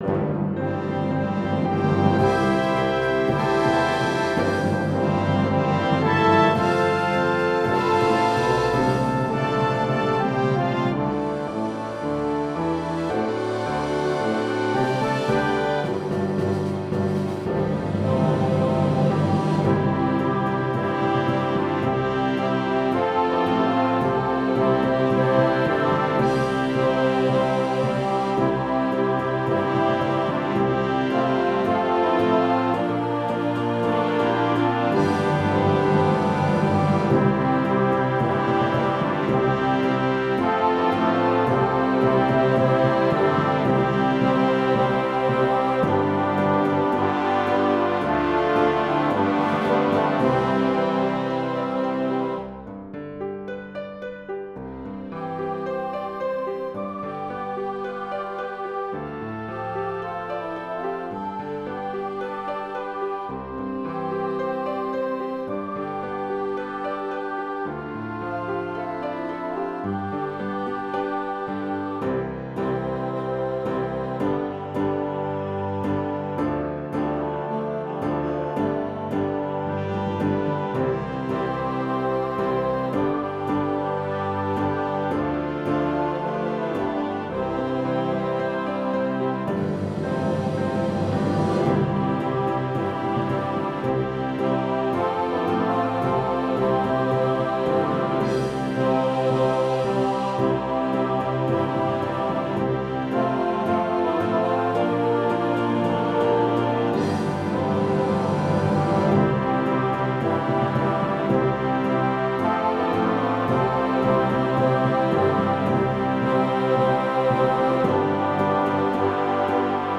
Christian, Gospel, Sacred, Christmas.